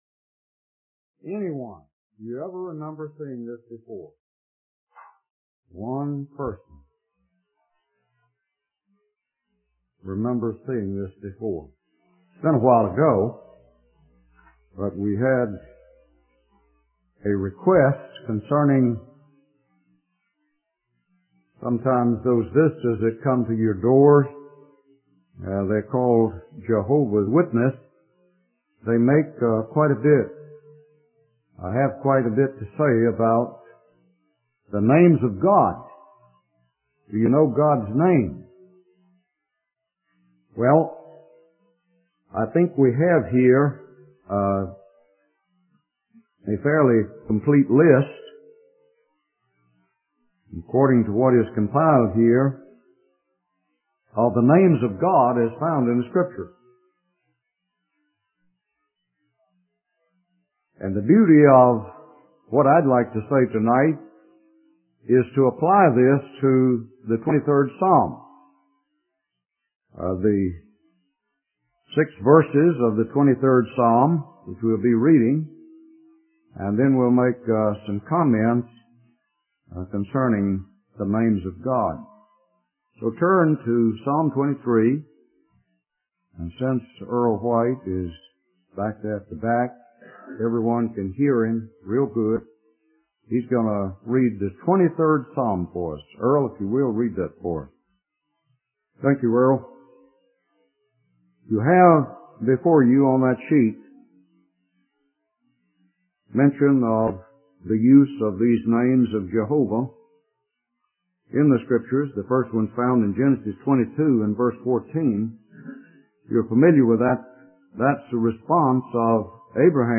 In this sermon, the preacher emphasizes the importance of relying on divine resources in times of distress. He shares a story about a missionary couple who found themselves surrounded by hostile forces and without any interest in the gospel.